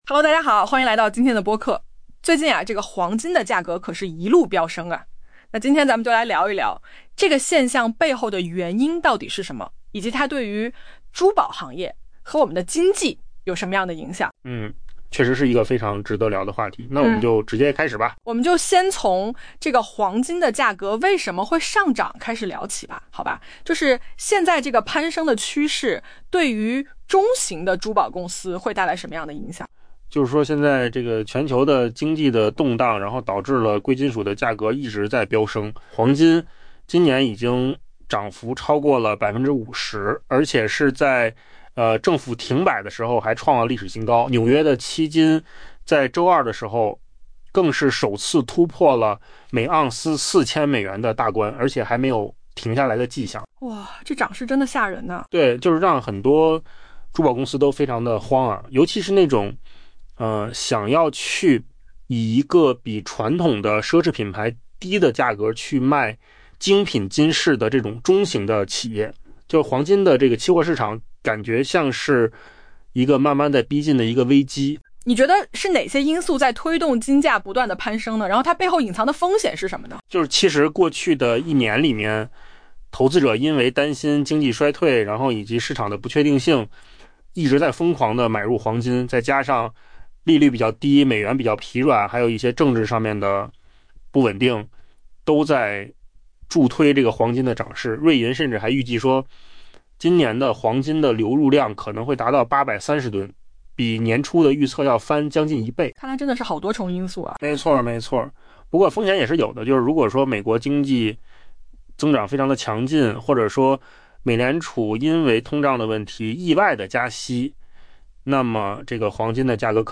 AI 播客：换个方式听新闻 下载 mp3 音频由扣子空间生成 全球经济震荡之中，贵金属价格正持续攀升。